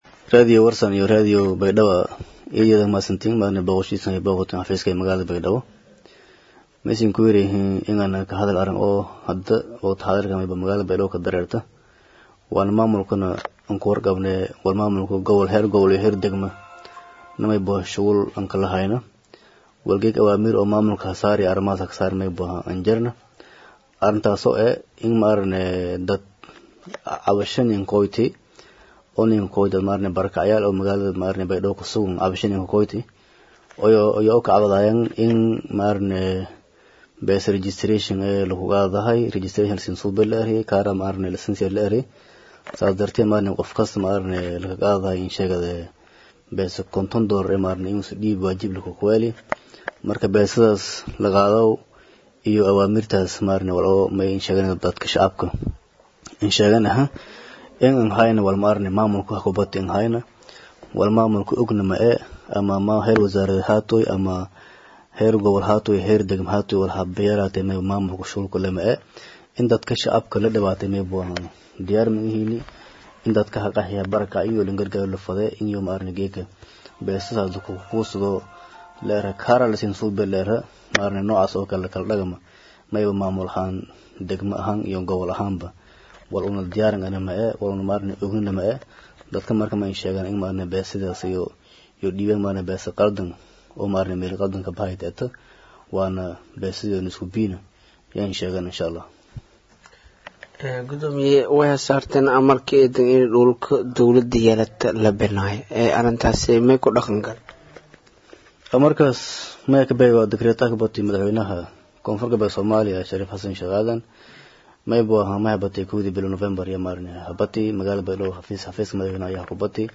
Baydhabo(INO)-Gudoomiyaha degmada Baydhabo  C/laahi Cali watiin oo maanta shir jaraa’id ku qabtey Gudaha magaalada Baydhabo ayaa kaga hadley in ay jiraan rag aysan ka soconin maamulka Gobolka iyo kan degmaa Baydhabo oo ay sheegeen in tiro koob ay ku sameeyeen  Barackyaasha Baydhabo isla markaana ay siinayaan Karar been abuur ah.
Dhageyso_-Wareysi-Xasaasi-ah-Gudoomiyaha-Degmada-Magalada-Baydhabo-Cabdulaahi-Cali-Watiin-oo-Ku-Qabta-shir-jaraa-.mp3